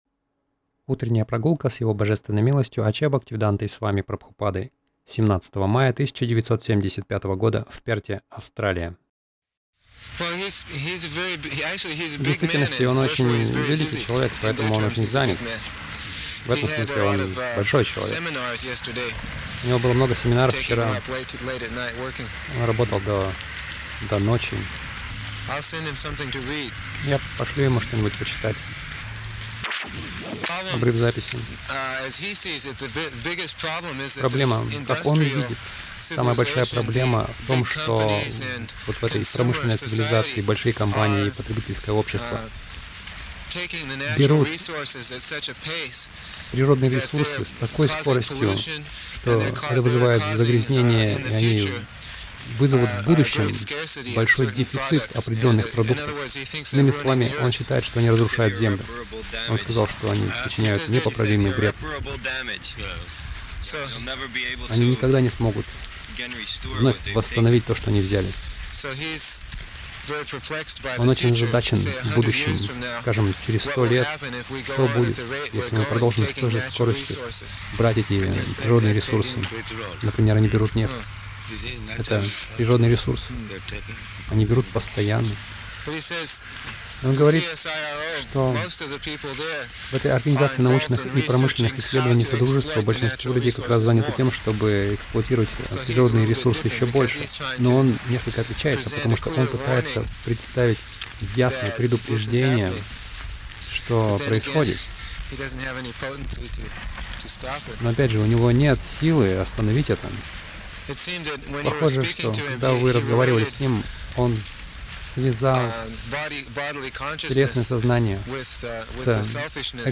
Милость Прабхупады Аудиолекции и книги 17.05.1975 Утренние Прогулки | Перт Утренние прогулки — Подумайте о своём будущем Загрузка...